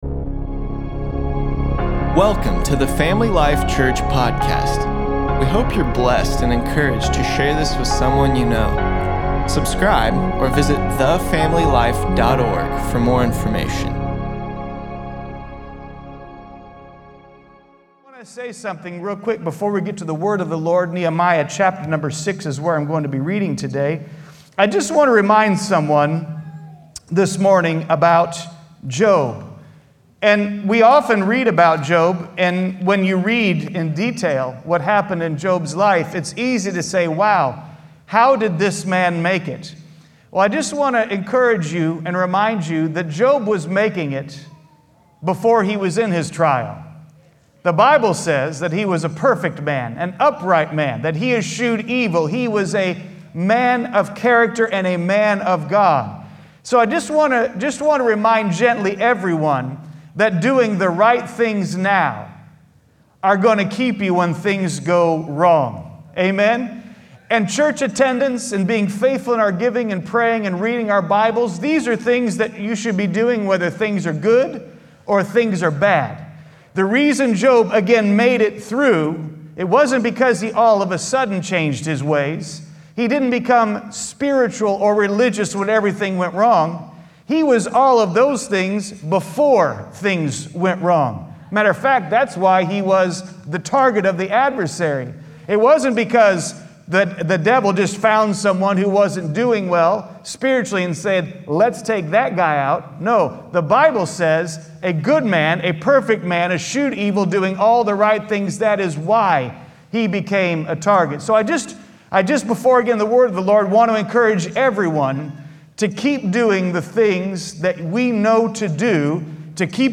9.27.20_sermon_p.mp3